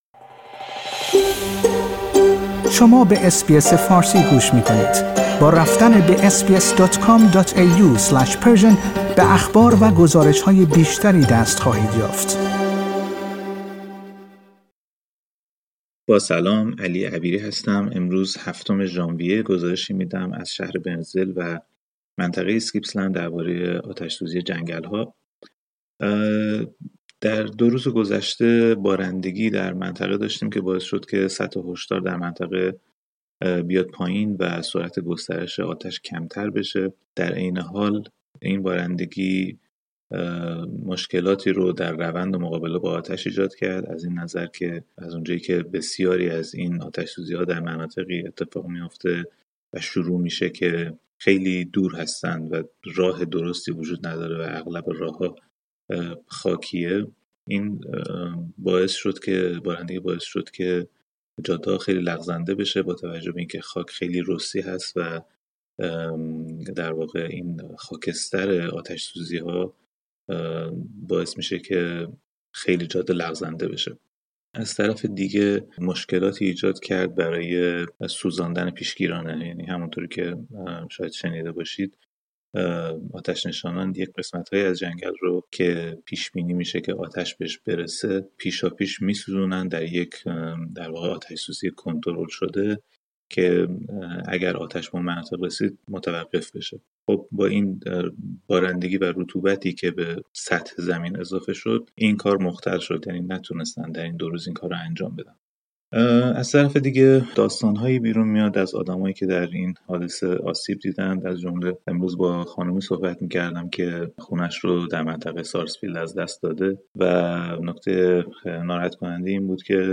او در این گزارش از تجربه شخصی خود در روبرو شدن با آتش سوزی های ویرانگر این منطقه می گوید.